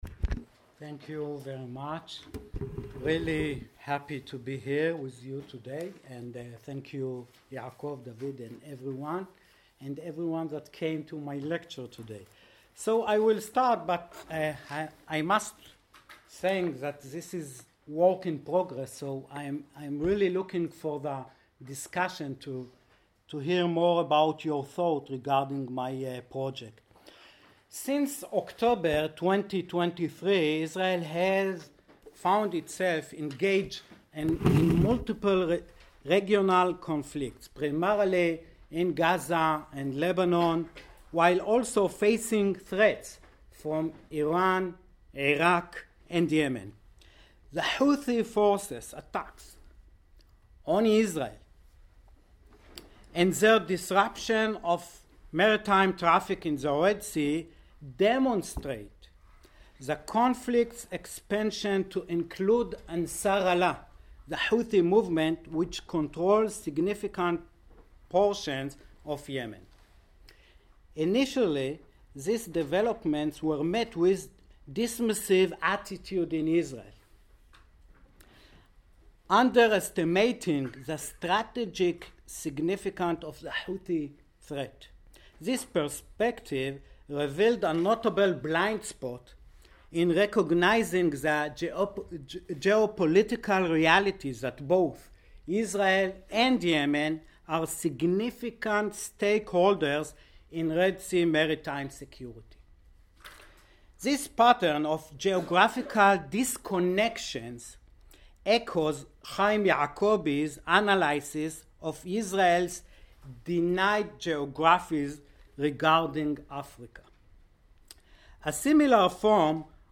This lecture examines the commercial legacy of the Ḥibshūsh family, a prominent Yemenite Jewish dynasty that played a pivotal role in the Red Sea basin trade from the 1880s to the 1970s